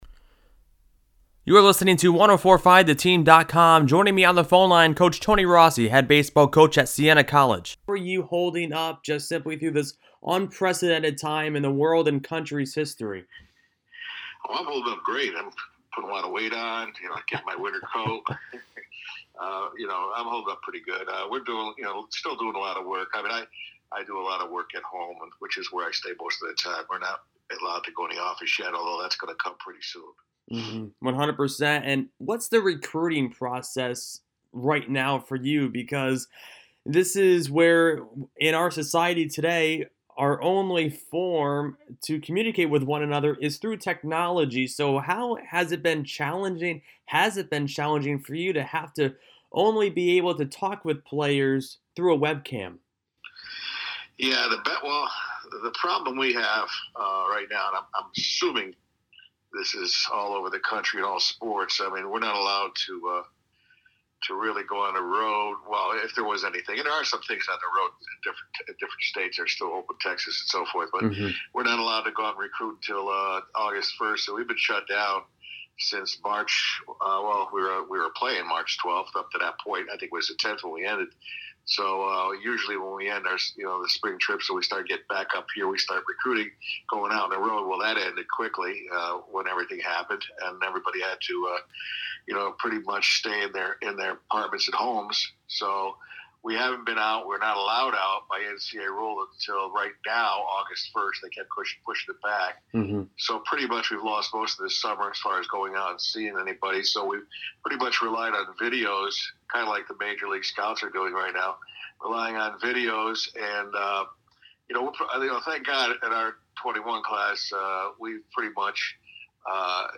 He also discussed the hard work, dedication, and sacrifice of his players went through to get ready for the season. You can check out the full audio interview below.